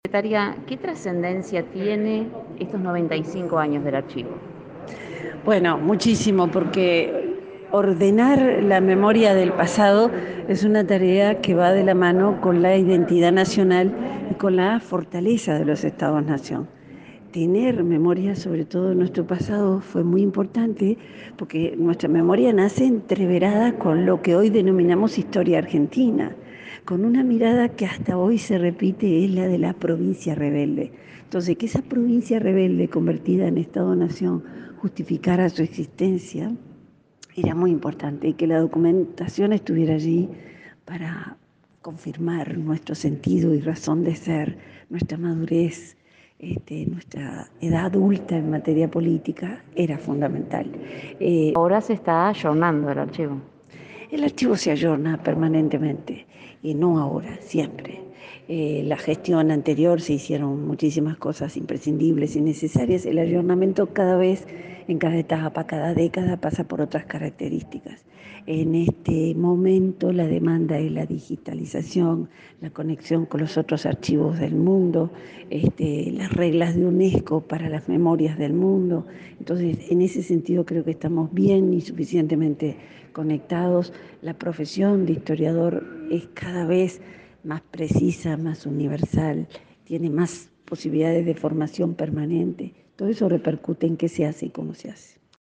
Declaraciones de la subsecretaria de Educación y Cultura